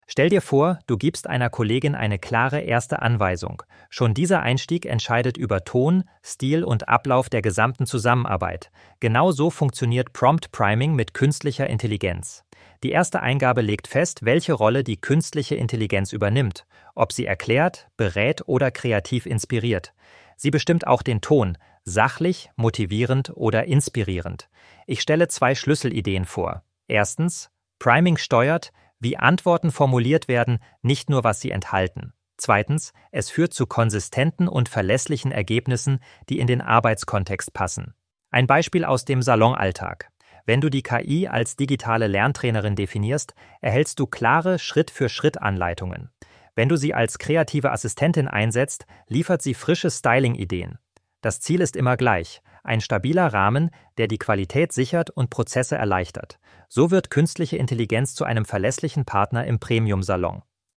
0203_Voiceover.mp3